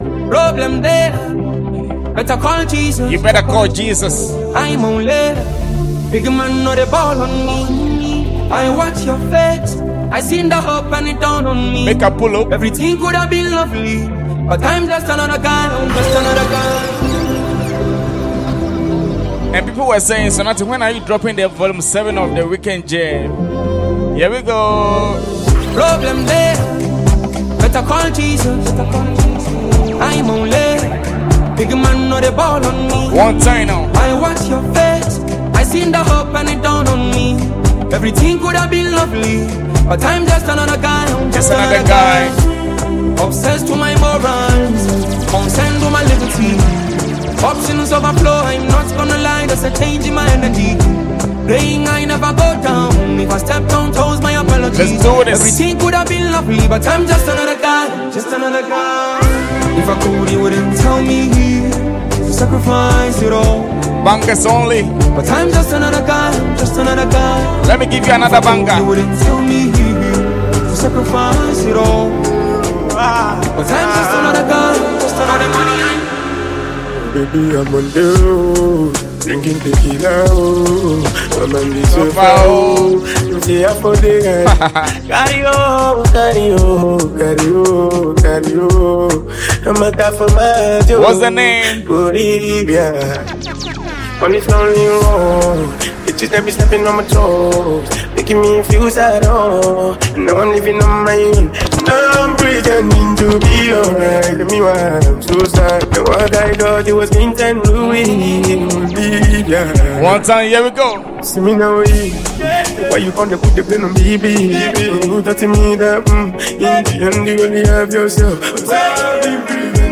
" a fire mix of Afrobeat and Ghana party anthems.